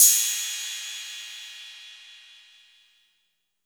Crashes & Cymbals
JuicyJ Crash.wav